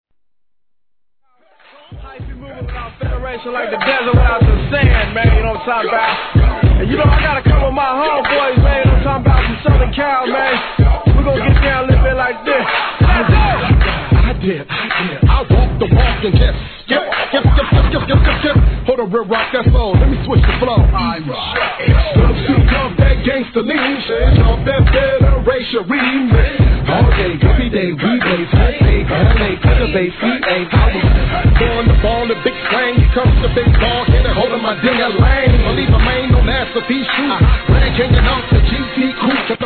G-RAP/WEST COAST/SOUTH
(105BPM)